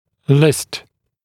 [lɪst][лист]список; вносить в список, составлять список; перечислять (в списке)